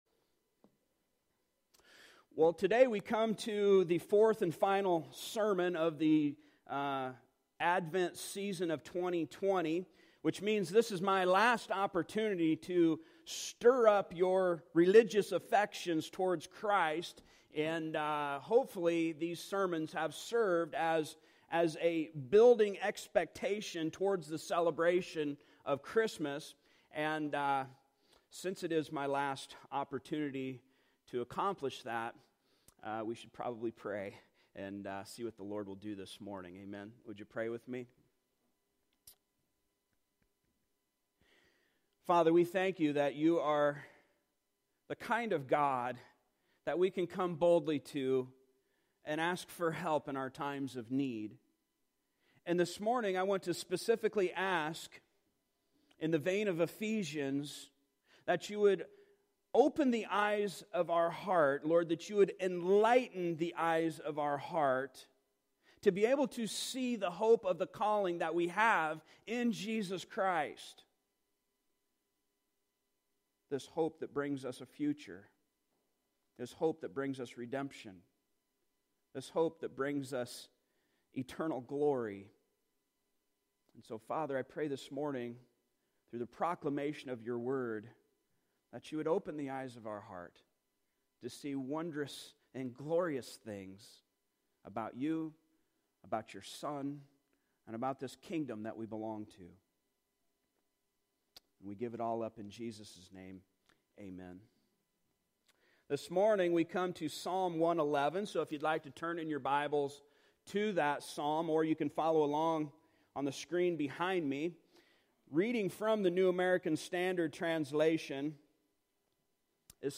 Advent 2020 Passage: Psalm 111 Service Type: Sunday Morning Topics